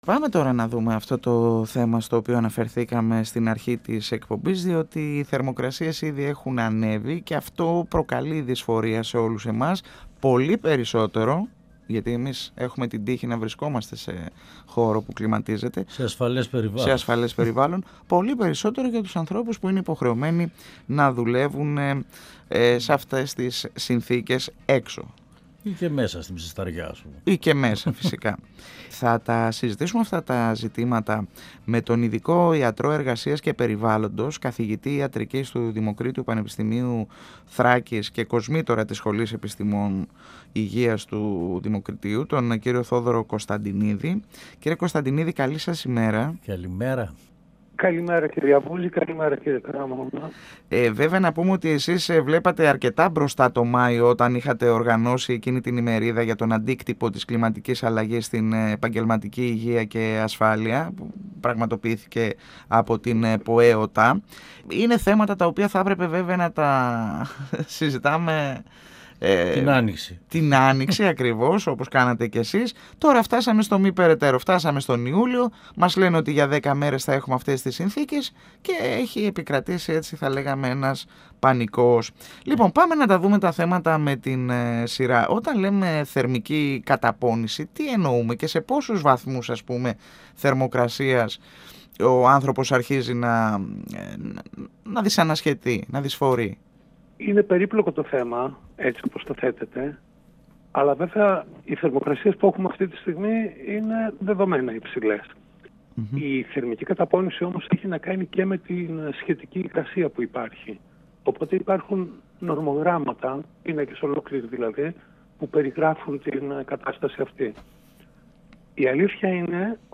μιλώντας στην εκπομπή «Εδώ και Τώρα» του 102FM της ΕΡΤ3.